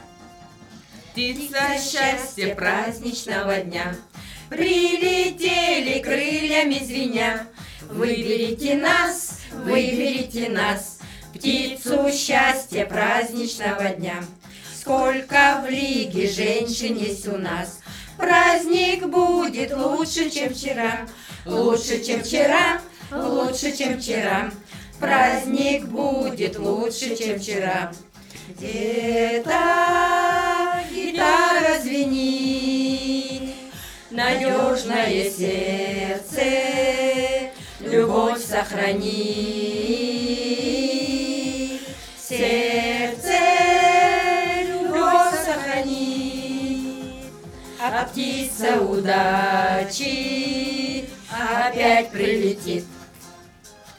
Записал 7 человек разом в маленькой студии. Есть ранние отражаения
Нужно было записать 7 женщин поющих в унисон под минусовку, чтобы сделать плюс.
Записал, но есть явные ранние отражения комнаты. Студия у меня 11 кв. соотношение 4:3 обита пирамидками 30 мм по боковым стенам только.
Записывал на Perception 220, поставил его в центре и вокруг женщины.